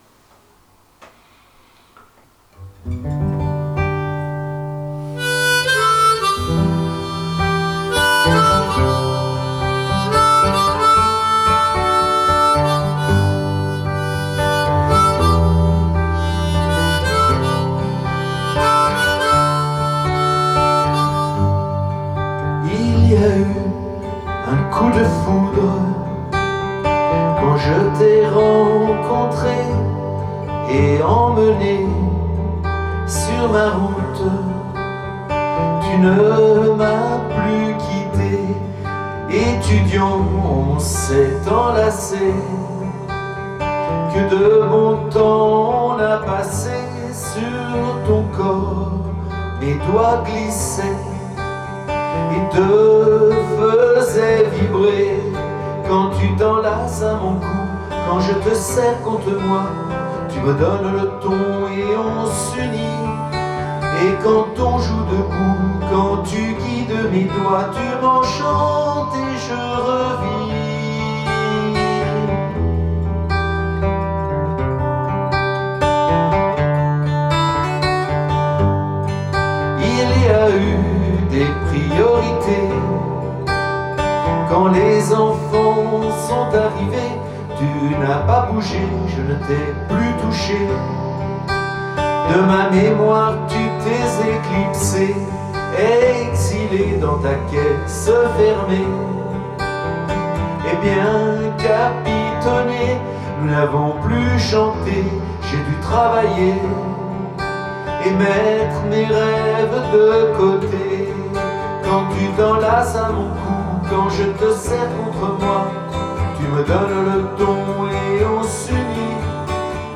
Ouverture en slowfox :